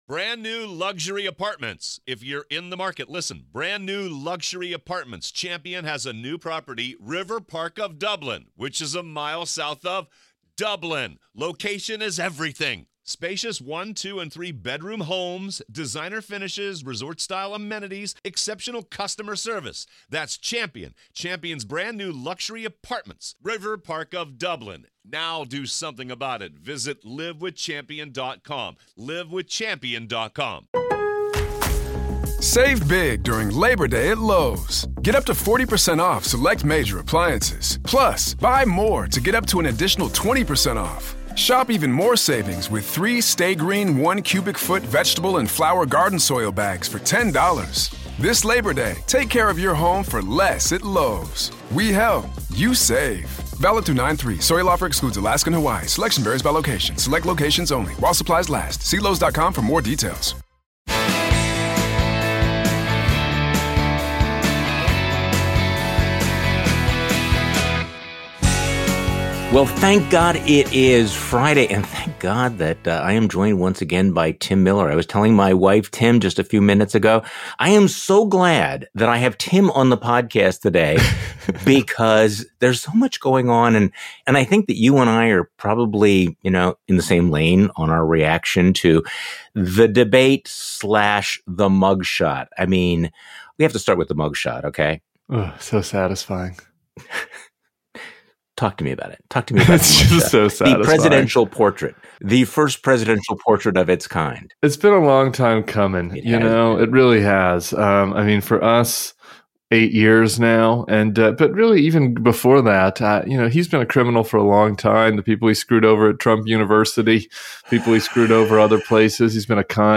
But this is all because of the choices Trump made, and because the party has refused to take every single off-ramp. Tim Miller joins Charlie Sykes for the weekend pod.